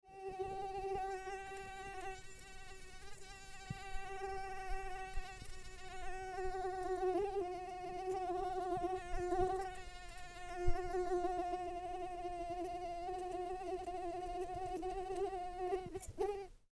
دانلود صدای پشه 4 از ساعد نیوز با لینک مستقیم و کیفیت بالا
جلوه های صوتی